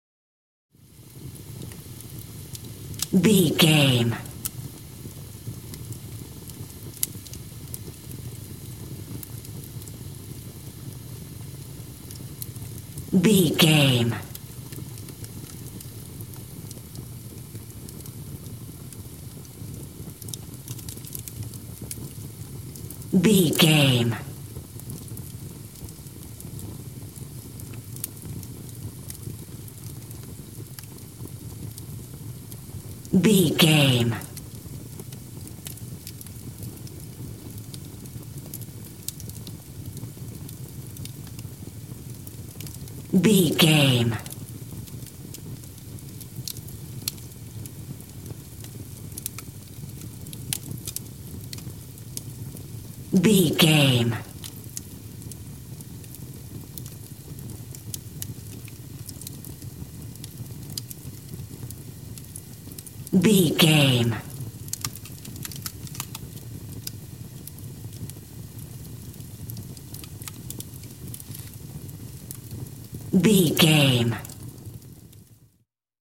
Fire with crakle
Sound Effects
torch
fireplace